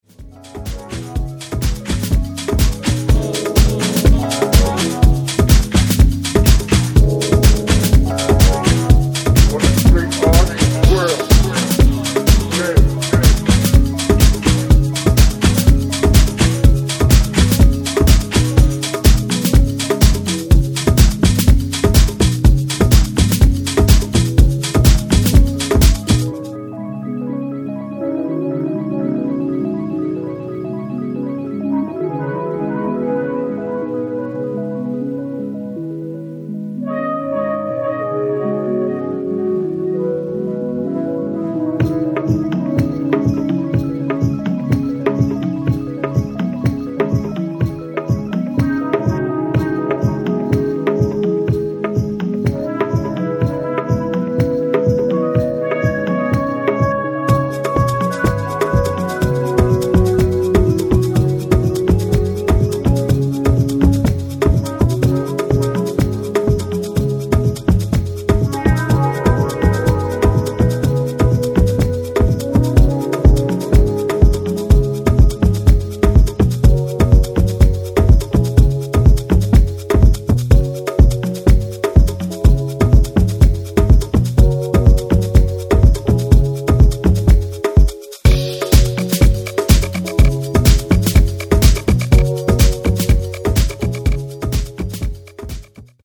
ジャンル(スタイル) DISCO / NU DISCO / DEEP HOUSE